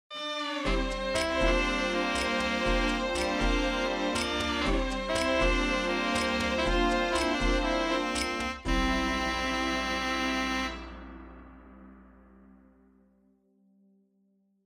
Plays mid of the track